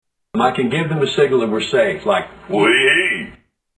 Tags: Prank Calls Gary Busey prank board Gary Busey Actor Celebrity